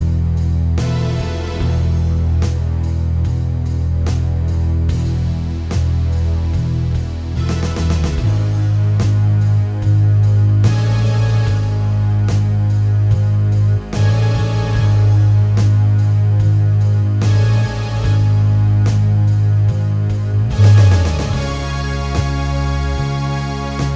Minus Electric Guitar Rock 6:28 Buy £1.50